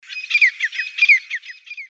Order Now Grey Francolin: The song is a far-carrying, loud and repetitive sound. This large quail-like bird is restricted to coastal areas in the north of the country.
Grey-francolin.mp3